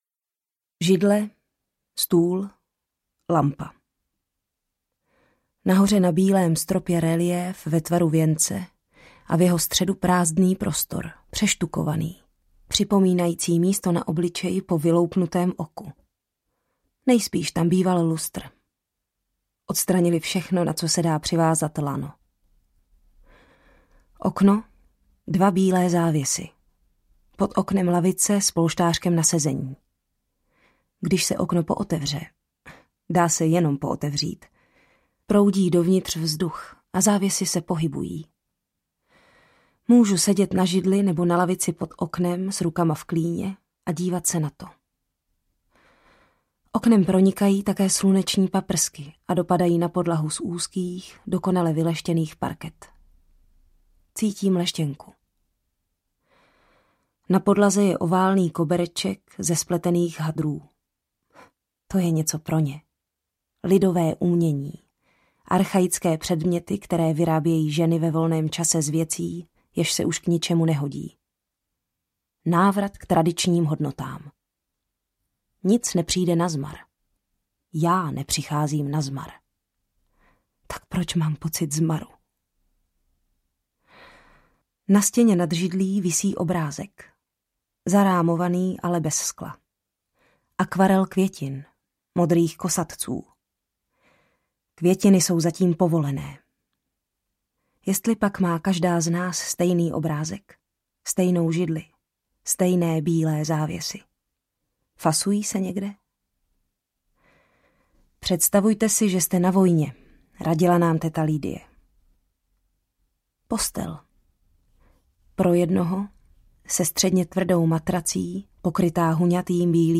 Příběh služebnice audiokniha
Ukázka z knihy
• InterpretZuzana Kajnarová, Martin Myšička